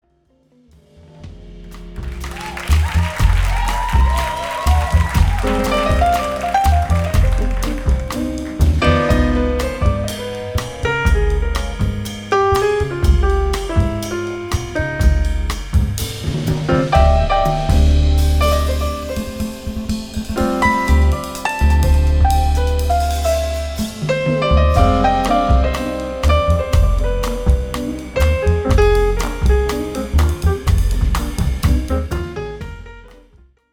jazz piano